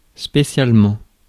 Ääntäminen
Ääntäminen France: IPA: [spe.sjal.mɑ̃] Haettu sana löytyi näillä lähdekielillä: ranska Käännöksiä ei löytynyt valitulle kohdekielelle.